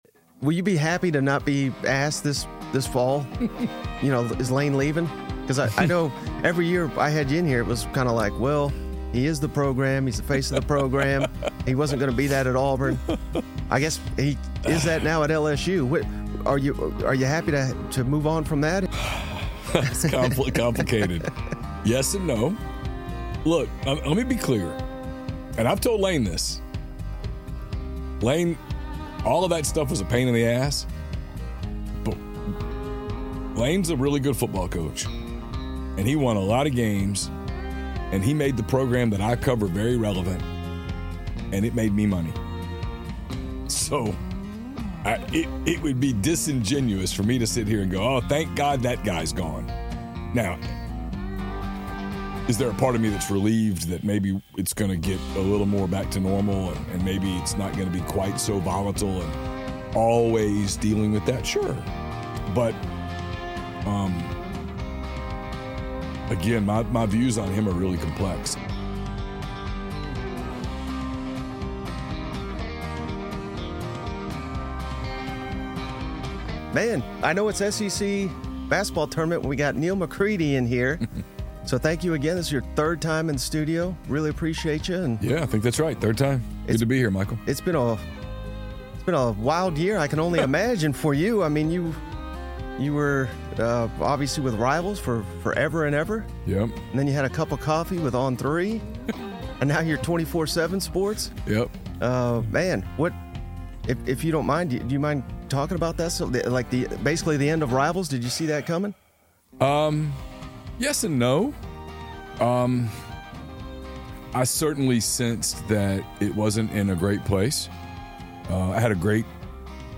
live from Nashville during the 2026 SEC Basketball Tournament